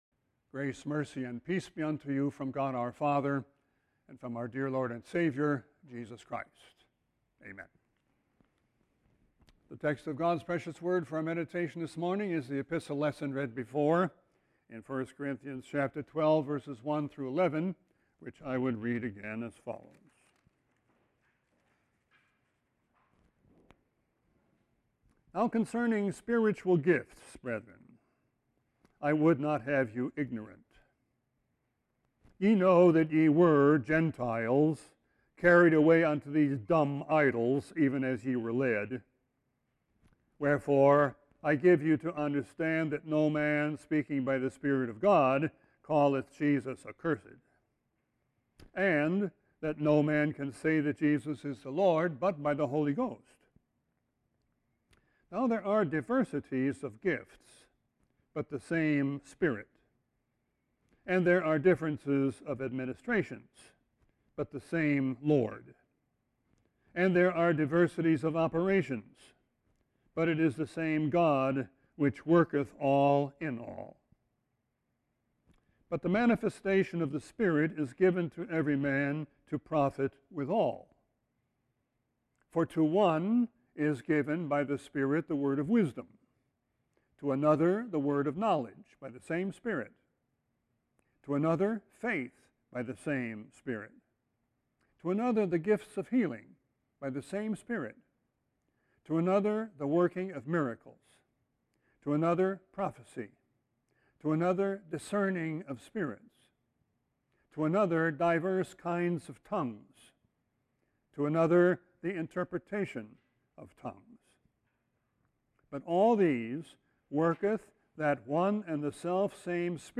Sermon 8-16-20.mp3